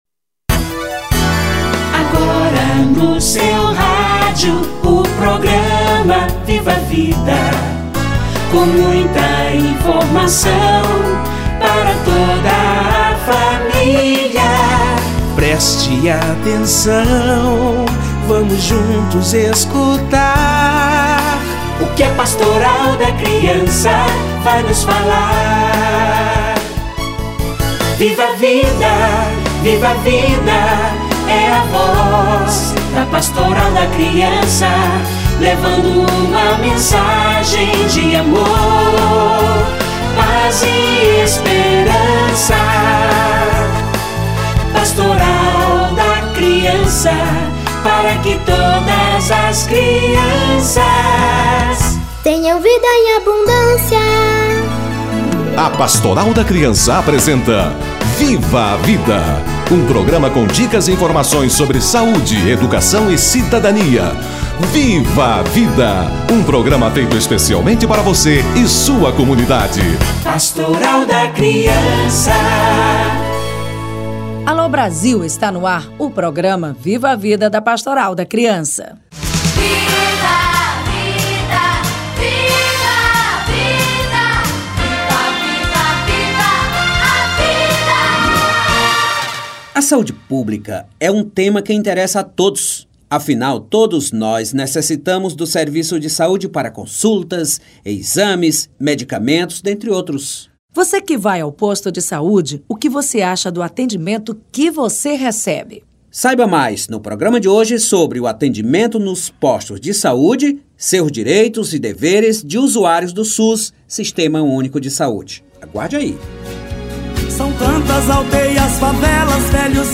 Direito à saúde - Entrevista